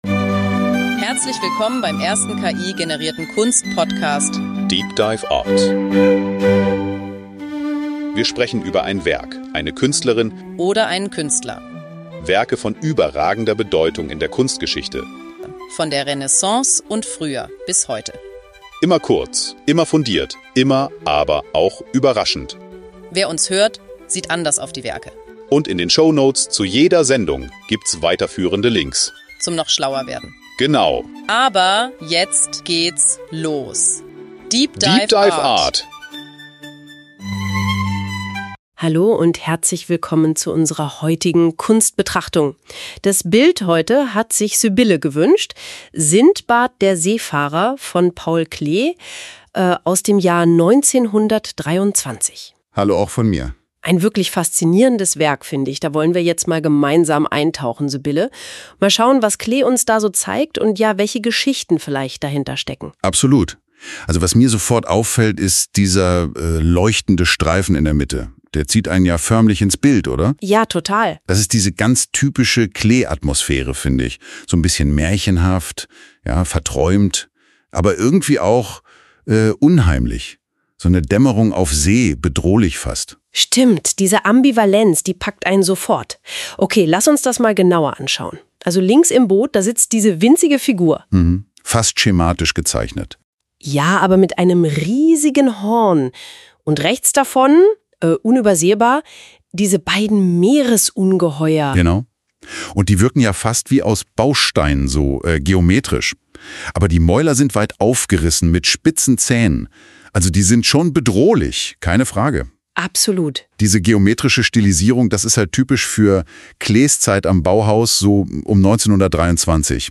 DEEP DIVE ART ist der erste voll-ki-generierte Kunst-Podcast.